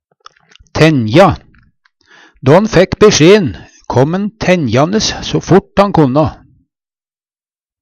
tenja - Numedalsmål (en-US)